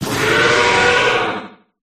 dracovish_ambient.ogg